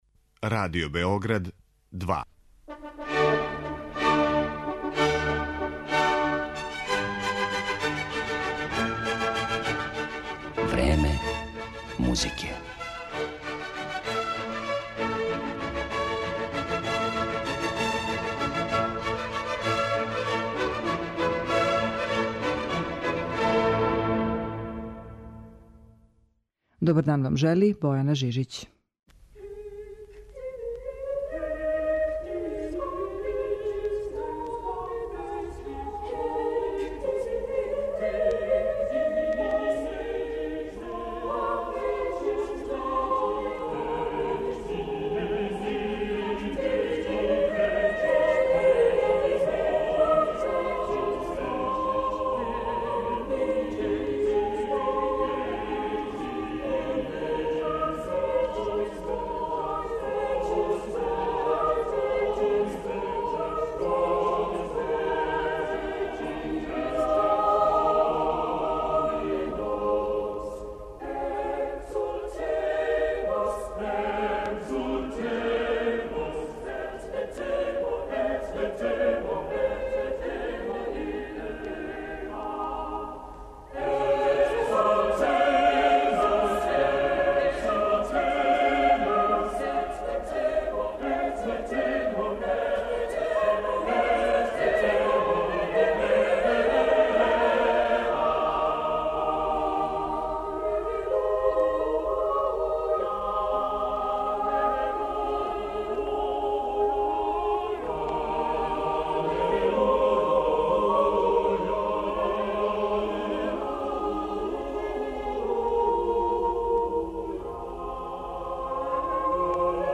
Eмисија класичне музике